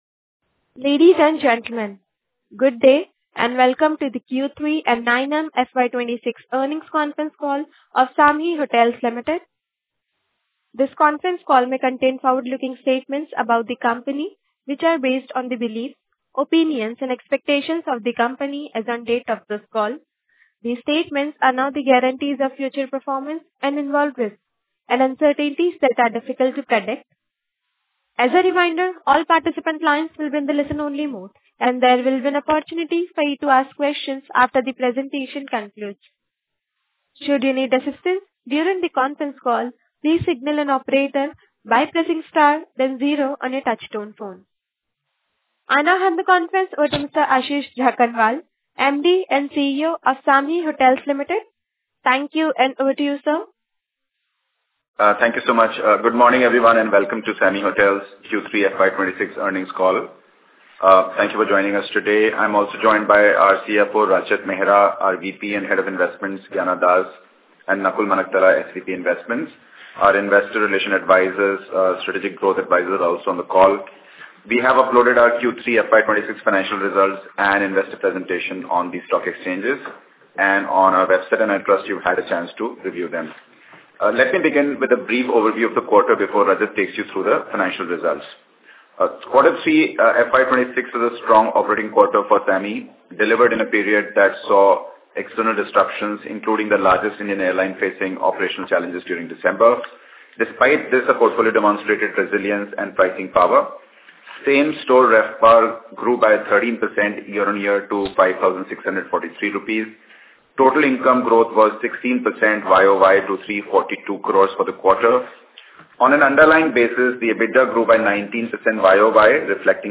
Earnings Call Details